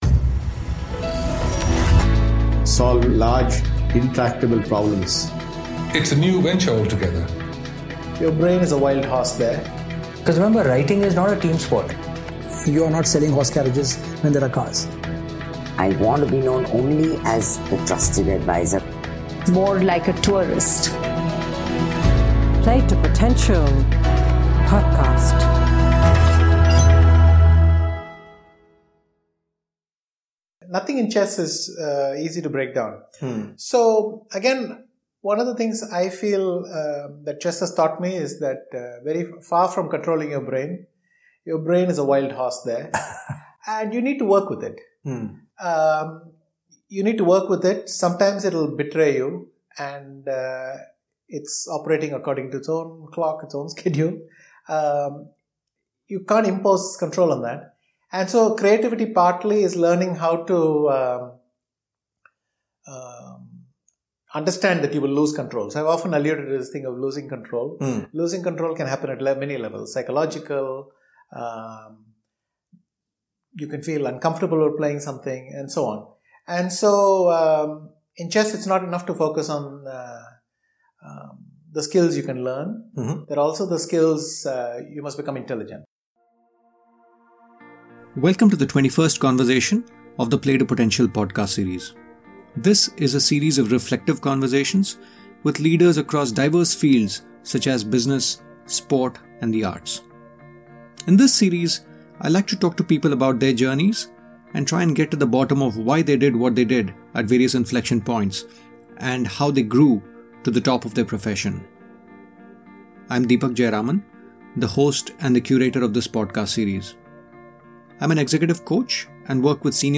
Chess is a great canvas for us to explore perspectives on these two questions. During the 90 odd minutes that I spent with him in Chennai, Vishy spoke candidly about how he got into Chess as a career, how he thought about his own development at various stages of his growth, how he harnesses his mind and leverages the right brain and the left brain, how EQ matters as one grows through the ranks, how he stays level-headed and grounded despite his towering achievements, his thoughts on harnessing human potential and more.